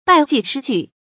敗績失據 注音： ㄅㄞˋ ㄐㄧˋ ㄕㄧ ㄐㄨˋ 讀音讀法： 意思解釋： 指事業失敗而無所憑依。